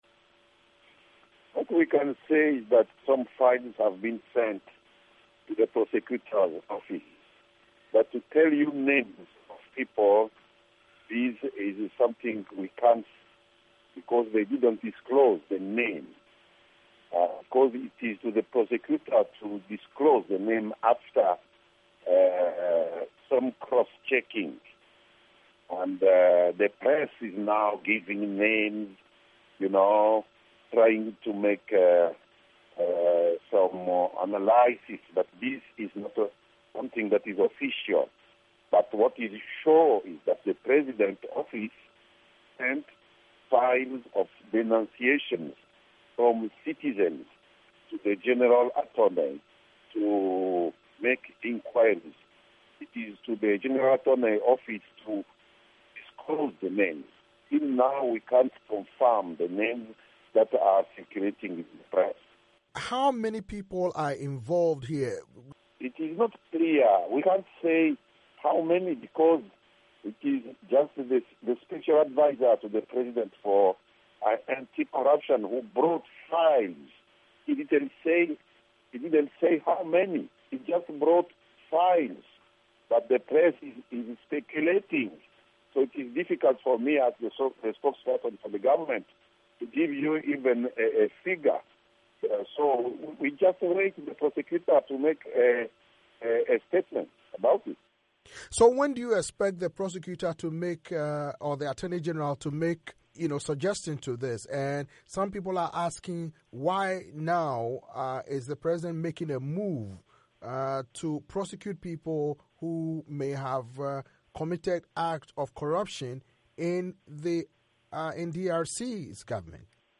interview with Lambert Mende, DRC's Information Minister